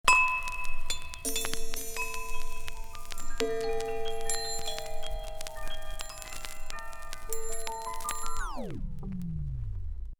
Dinner Bell.wav